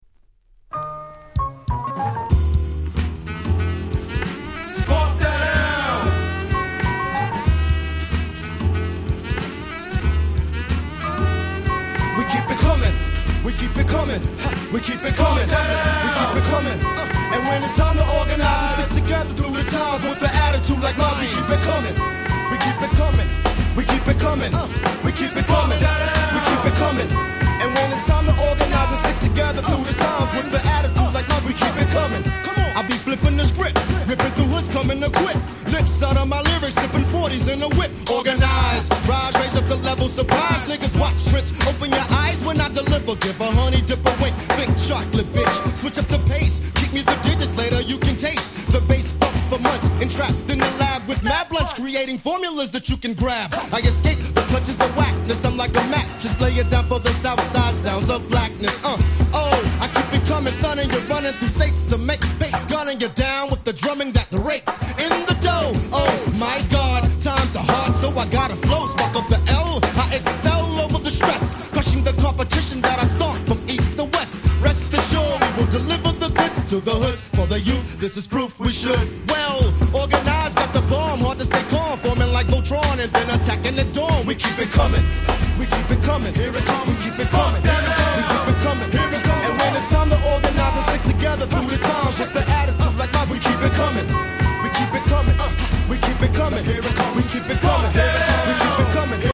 94' Smash Hit Hip Hop !!